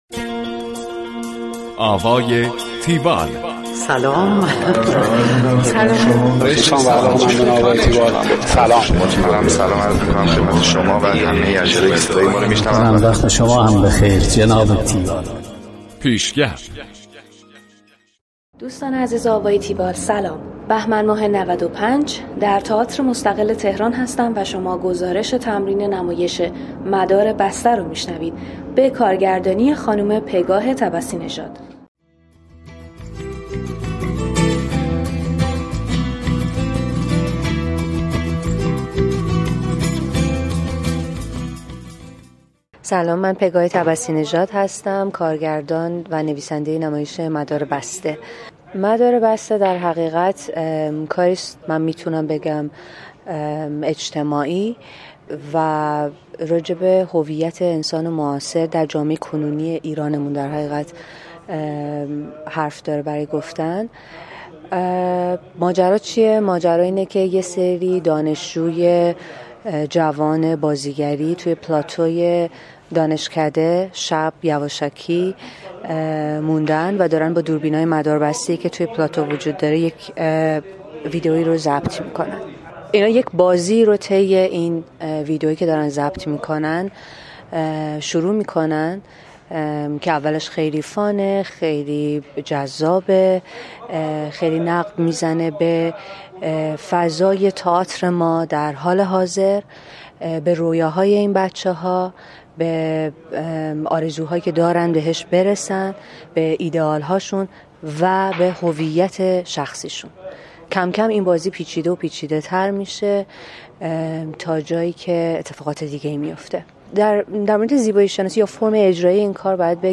گزارش آوای تیوال از نمایش مدار بسته
گفتگو با